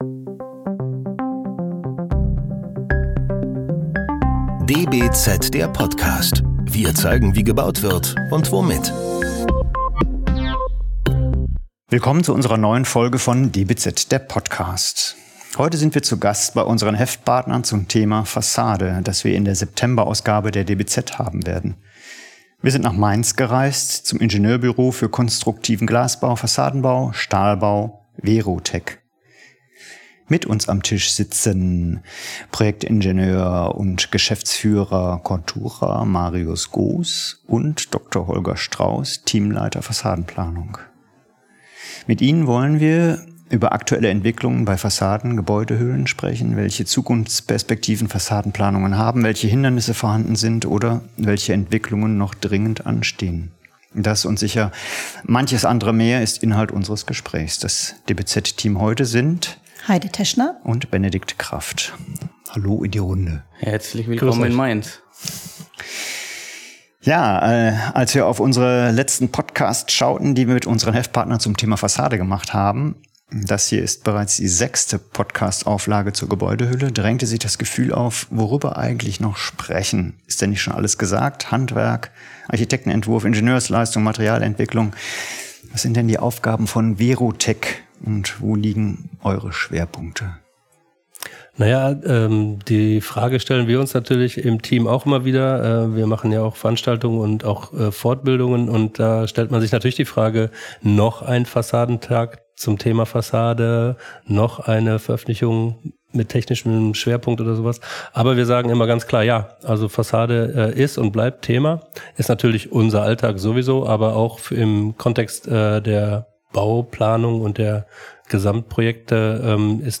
Dazu sind wir nach Mainz zu Verrotec gereist, einem Ingenieurbüro für Konstruktiven Glas-, Fassaden- und Stahlbau, unserem Heftpartner der September-Ausgabe der DBZ.